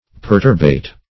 Search Result for " perturbate" : The Collaborative International Dictionary of English v.0.48: Perturbate \Per"tur*bate\, v. t. [From L. perturbatus, p. p.]